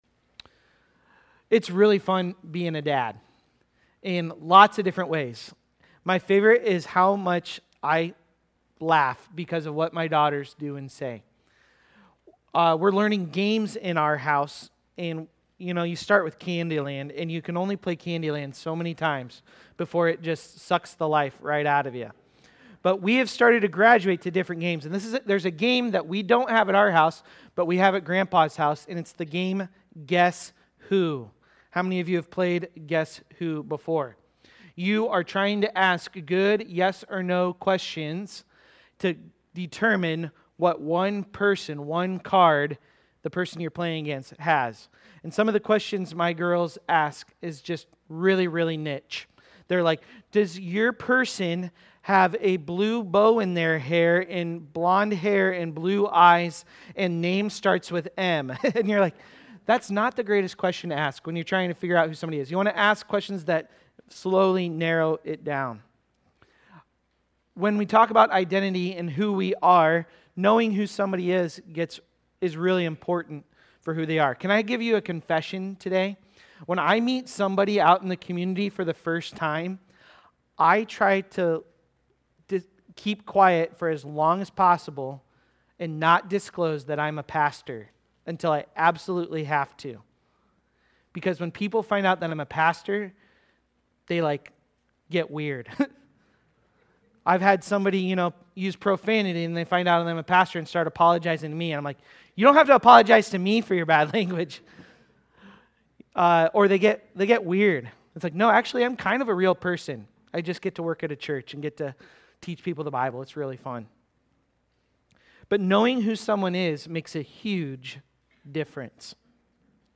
Solid-Rock-Stumbling-Block-Sermon-Audio.mp3